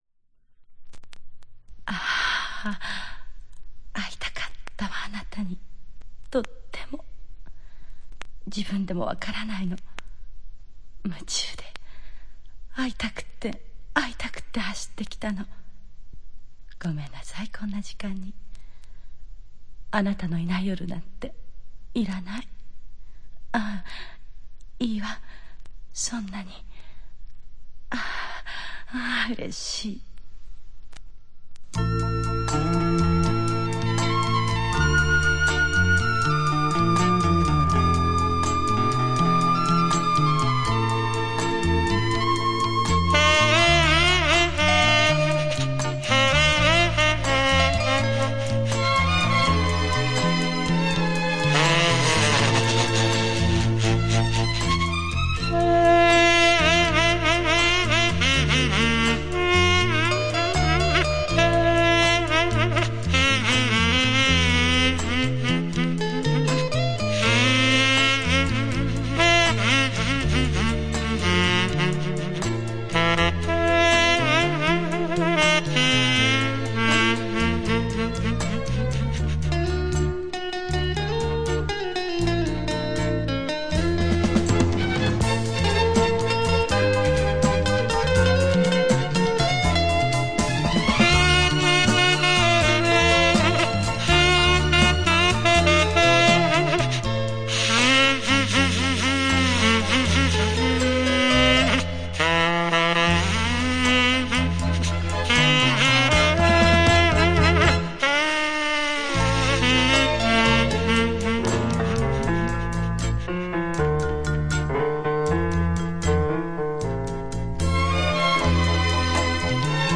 萨克斯 VA
Жанр: JPop
Носитель: LP
Код класса состояния винила: VG (RCM)